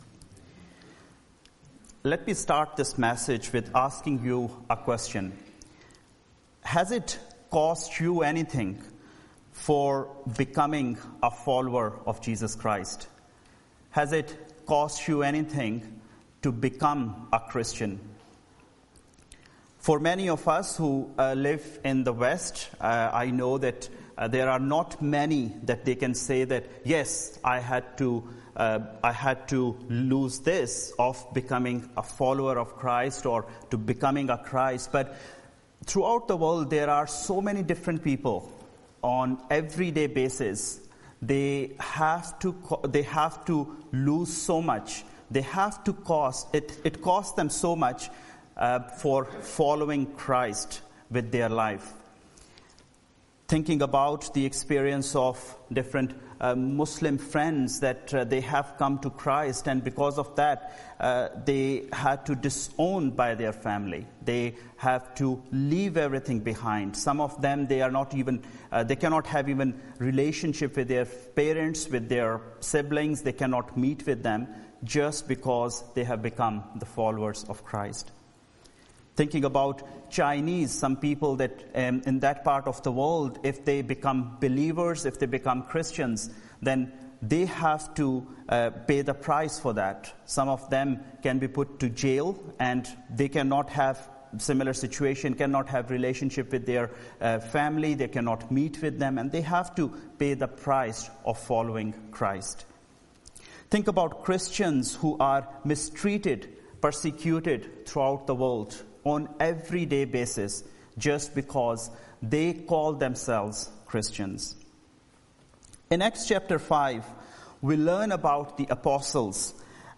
Series: Sermons That Shook Society Passage: Acts 5:12-42 Service Type